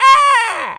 Animal_Impersonations
crow_raven_squawk_04.wav